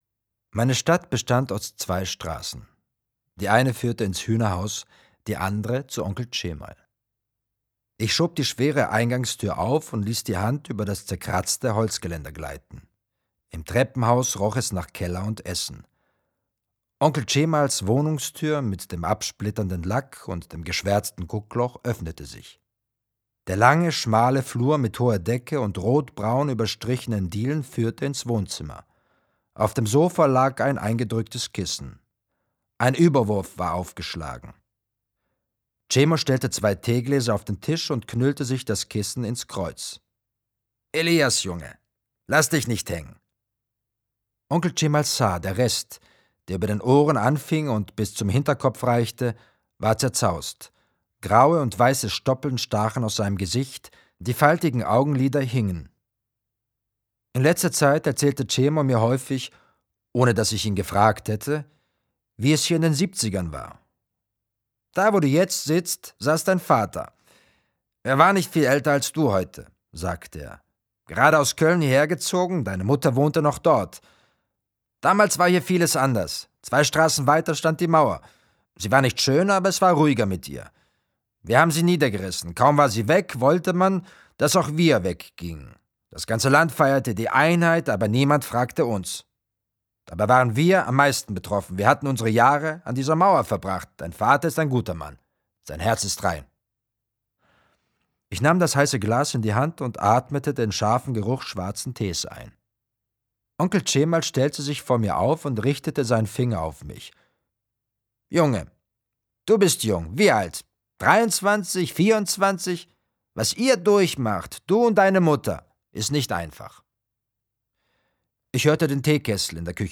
Die Ungehaltenen 5 CDs Deniz Utlu (Autor) Stipe Erceg (Sprecher) Audio-CD 2014 | 1.